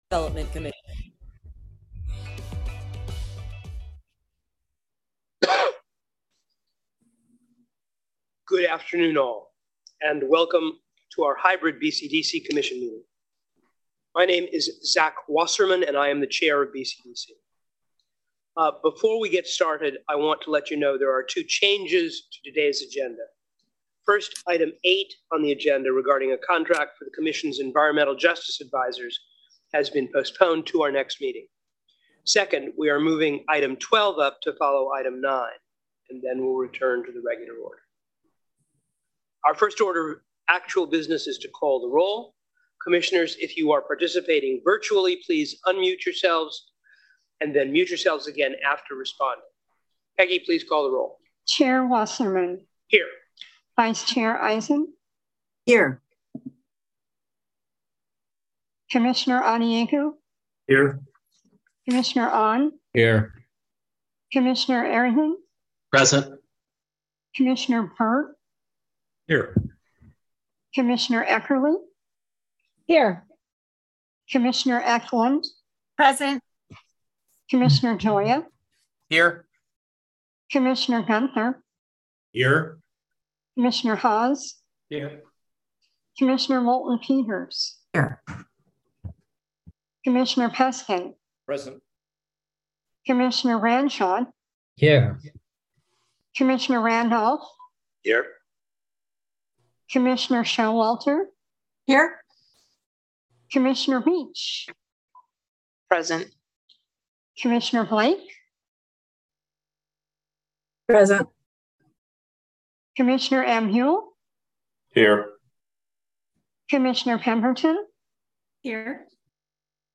February 16, 2023 Commission Meeting | SF Bay Conservation & Development
To maximize public safety while maintaining transparency and public access, members of the public can choose to participate either virtually via Zoom, by phone, or in person at the location listed above.